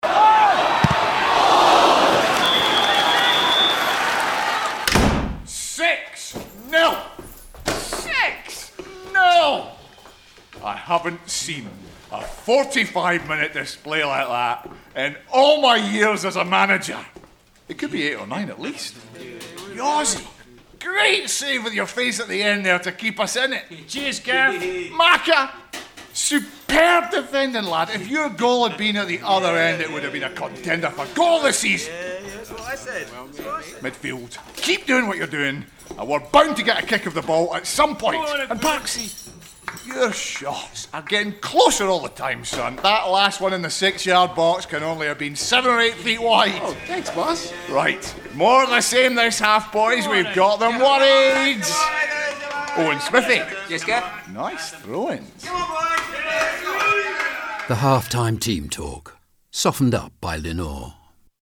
But what if it could soften just about anything? The campaign was expertly mixed by the engineers at Redback.
To really put the listener inside an angry football dressing room, the football manager had to be Scottish. An angry Spaniard or Chilean just wouldn’t have been the same.